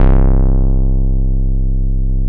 26SYN.BASS.wav